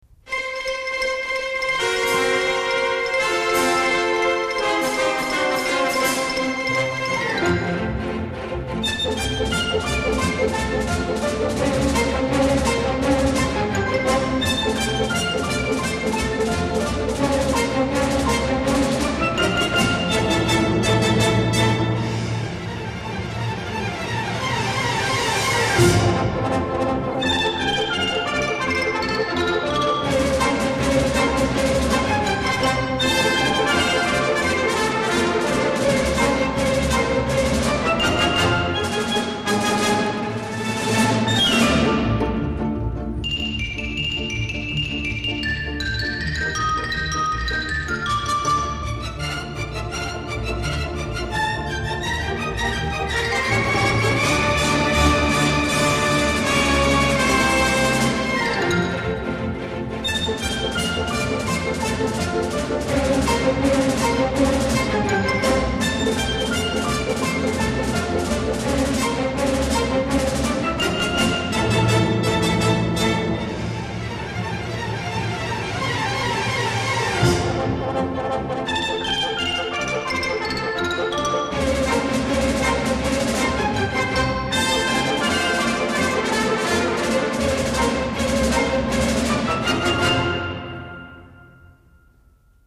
音效好的让人难以抗拒